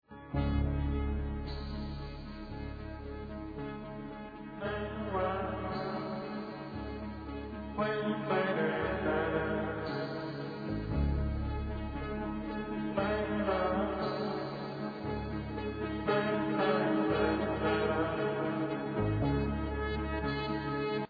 Recorded at Nemo Studios, London, England, 1977